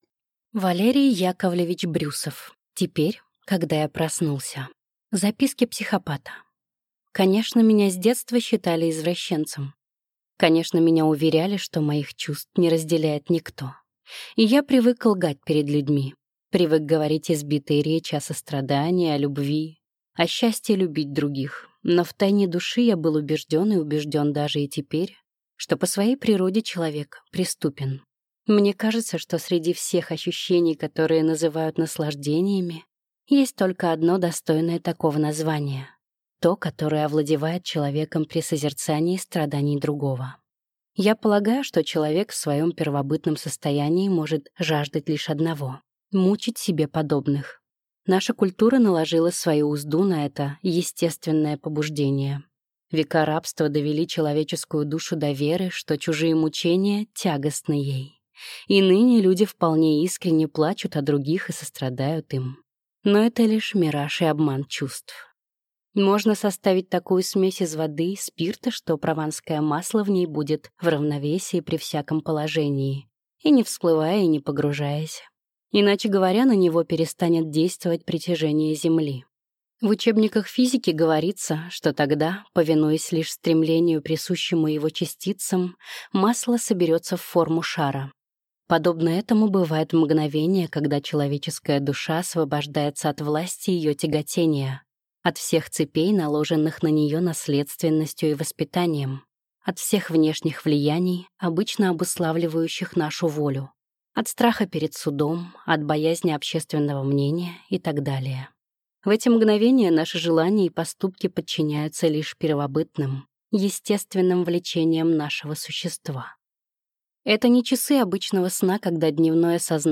Аудиокнига Теперь, – когда я проснулся…